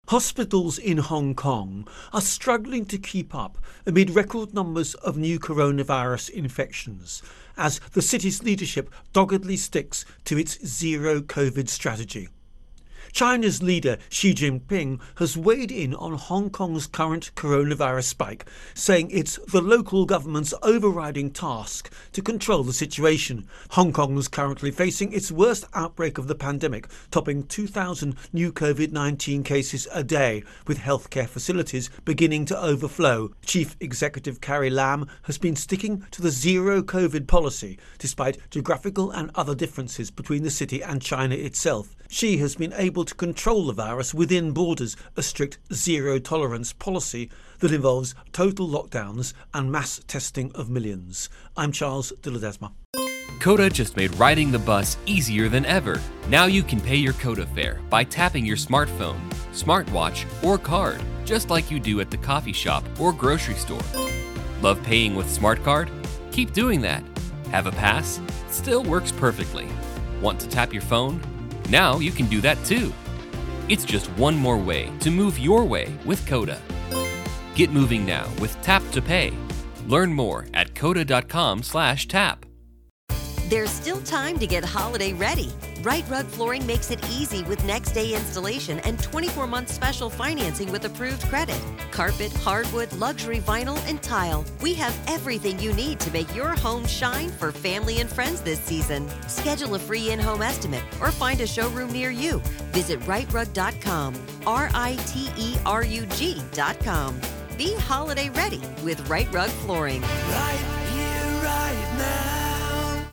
Virus Outbreak-Hong Kong Intro and Voicer